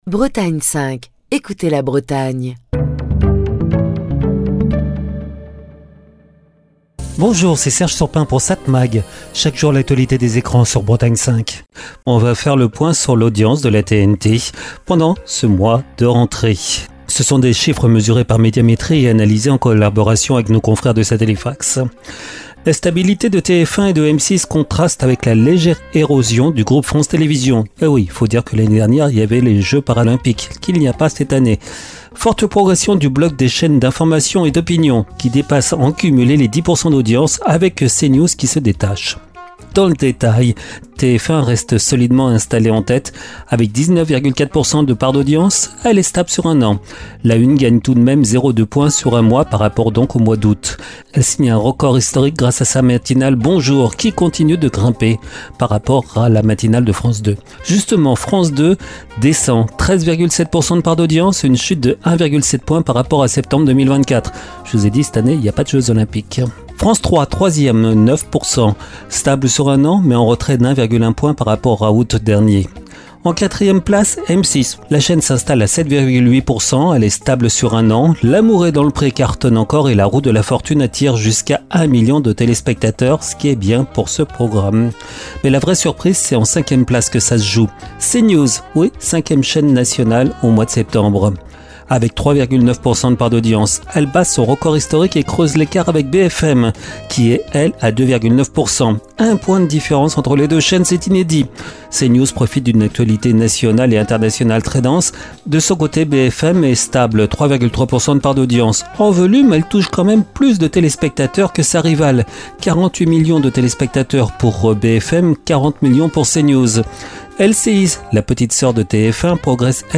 Chronique du 1er octobre 2025.